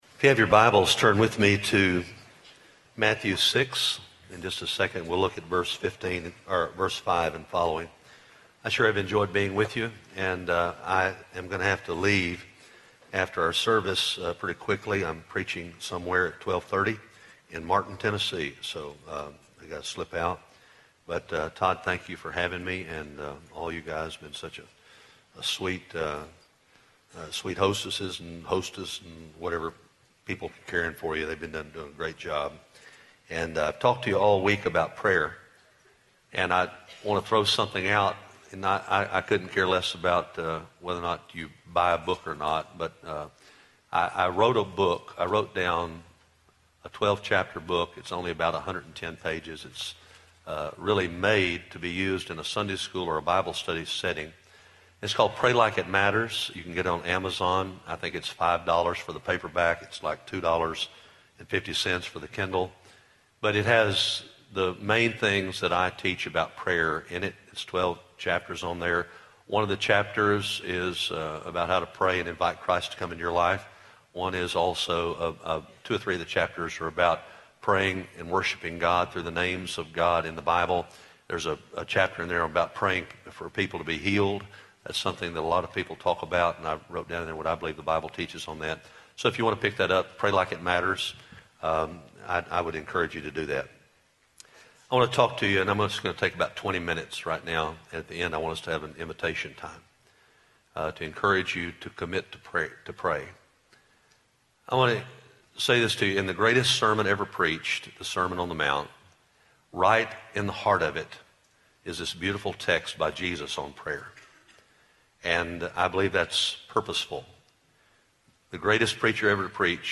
Faith in Practice Chapel:
Address: "Prayer and Fasting" from Matthew 6:5-18 Recording Date: Oct 16, 2015, 10:00 a.m. Length: 33:54 Format(s): MP3 ; Listen Now Chapels Podcast Subscribe via XML